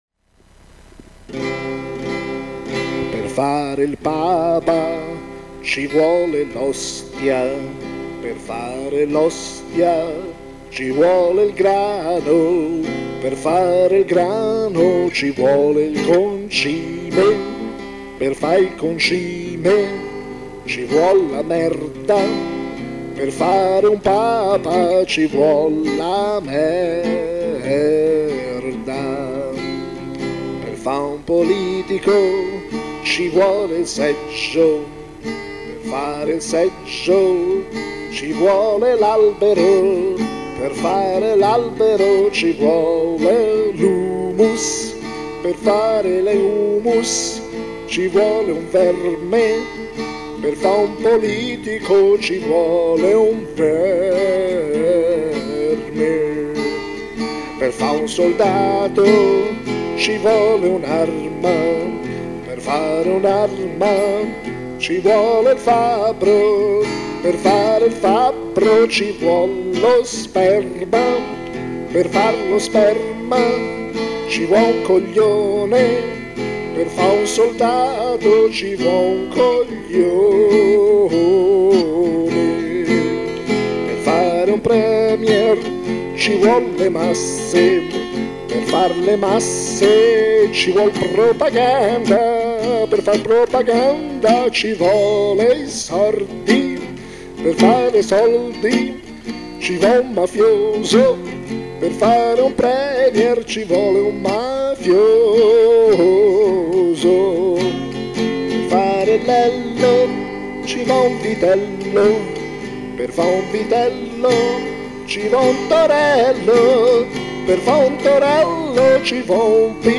Una cover